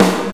XR GATE SNAR.wav